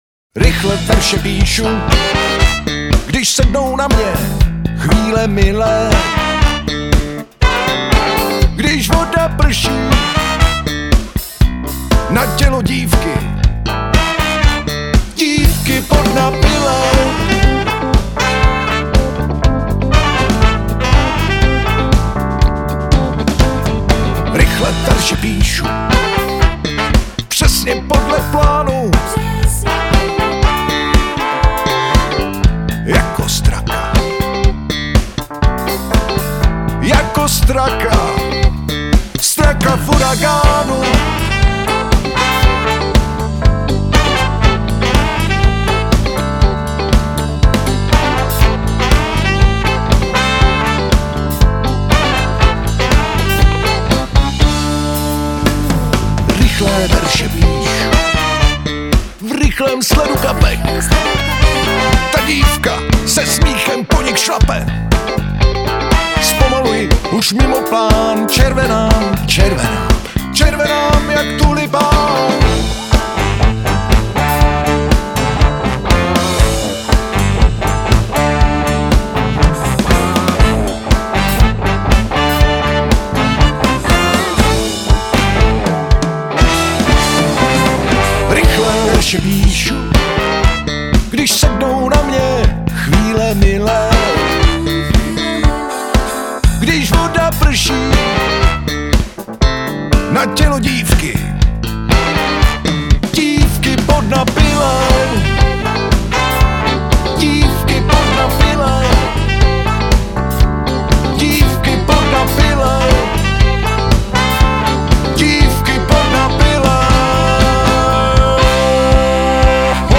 Alt saxofon:
Tenor saxofon:
Trubka:
Pozoun: